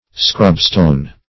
Scrubstone \Scrub"stone`\, n. A species of calciferous sandstone.